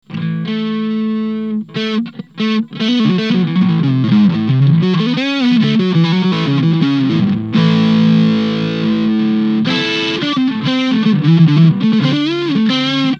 humbucker neck
TS808_humb_neck.mp3